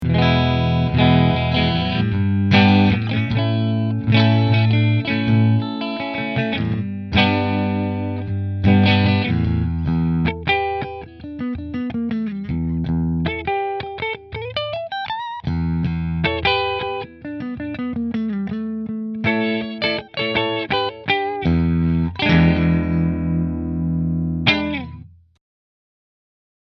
• 2 Custom Wound Humbuckers
New Orleans Guitars Voodoo Custom Natural Middle Through Fender